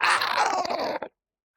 Minecraft Version Minecraft Version 25w18a Latest Release | Latest Snapshot 25w18a / assets / minecraft / sounds / mob / strider / death3.ogg Compare With Compare With Latest Release | Latest Snapshot
death3.ogg